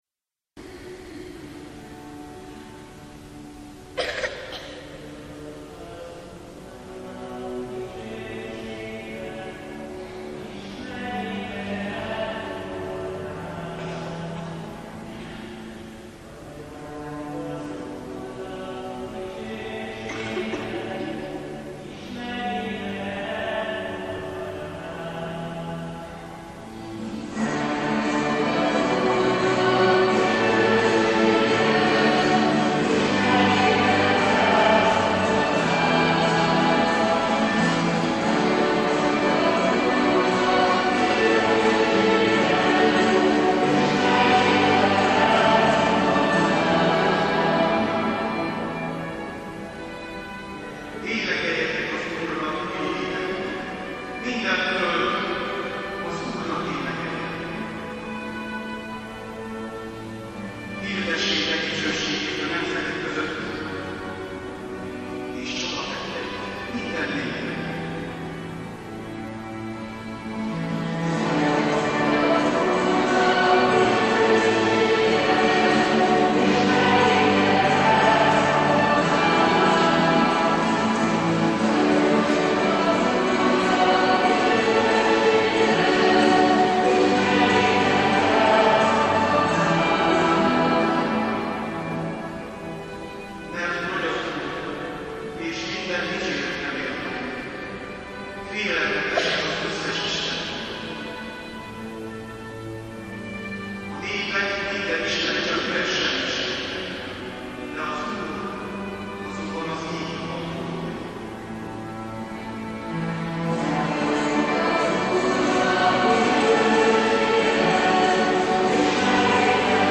furulya
cselló, billentyű
mp3 (amatőr hangfelvétel)